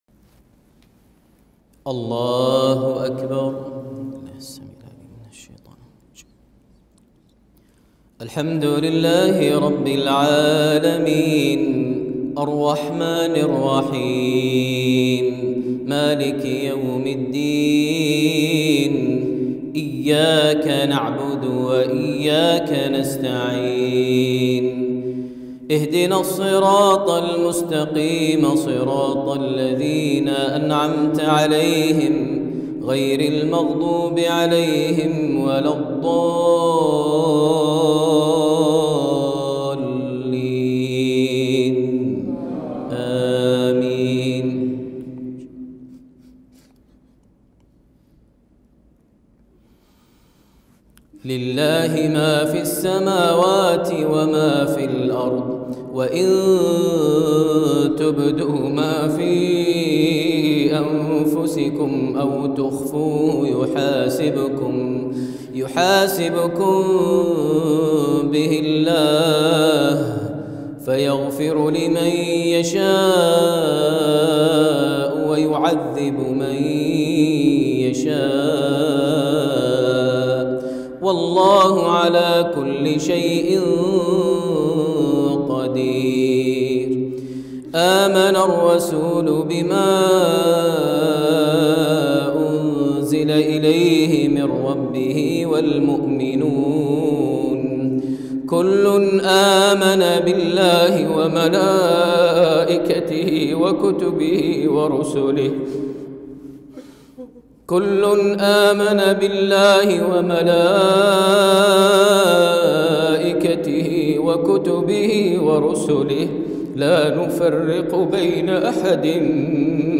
الصلاة التي أبكى فيها المعيقلي ورتل بأسلوب عجيب من خواتيم سورة البقرة > زيارة الشيخ ماهر المعيقلي لدولة روسيا - موسكو عام ١٤٣٦هـ > المزيد - تلاوات ماهر المعيقلي